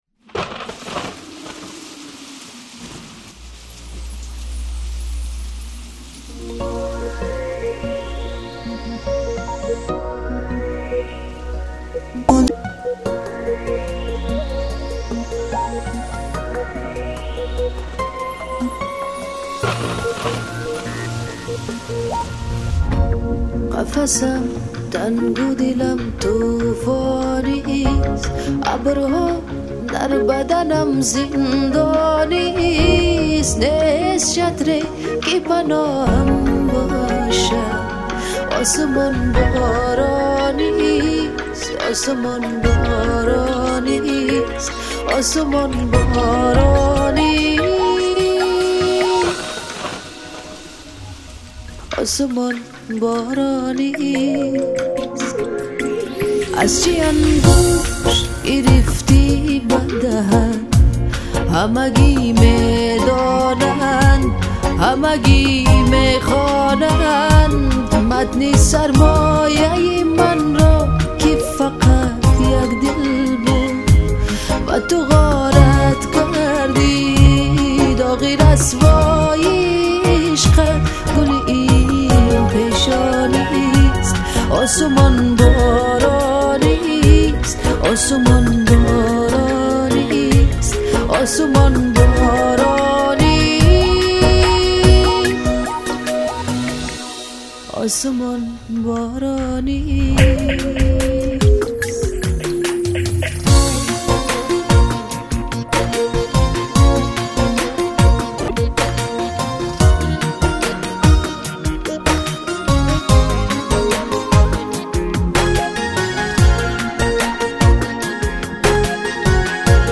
Главная » Музыка » Pop
таджиская музыка